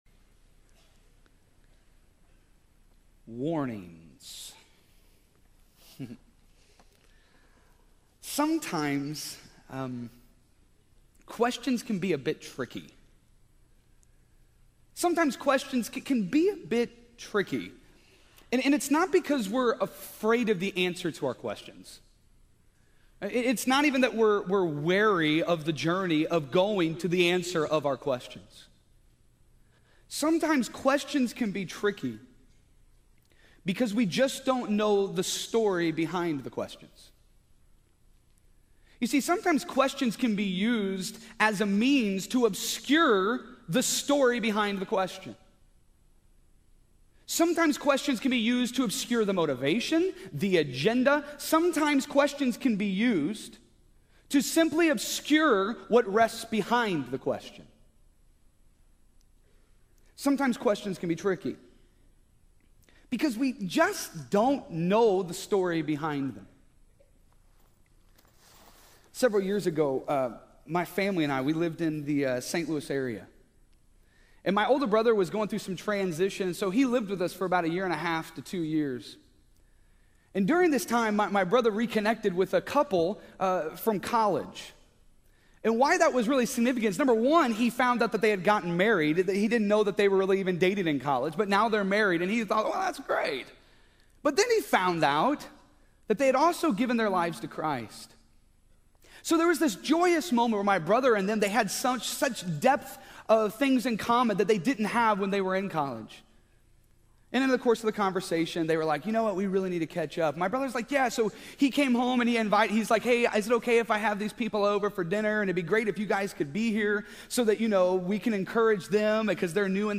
TITLE: Jesus Warns Us Against: Failing to Forgive (Matthew 18:5-9, 15-20) PLACE: College Heights Christian Church (Joplin, MO) DESCRIPTION: What do you do when your brother becomes your enemy?